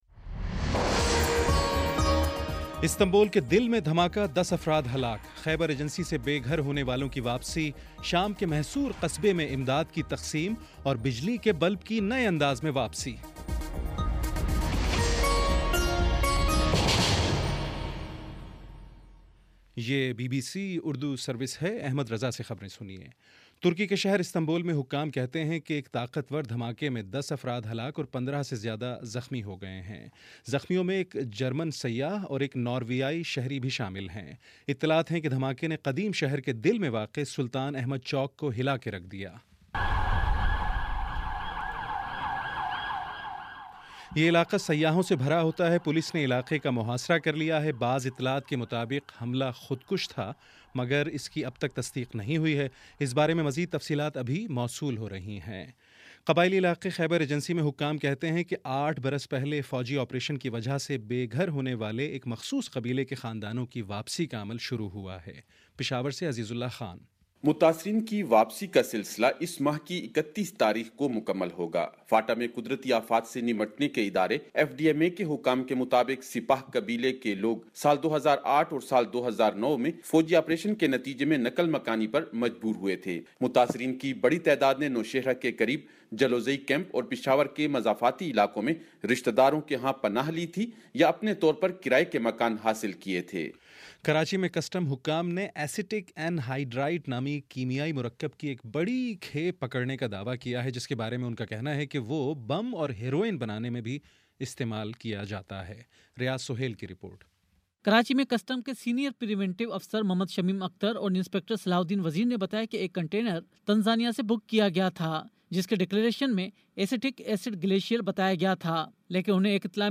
جنوری 12 : شام پانچ بجے کا نیوز بُلیٹن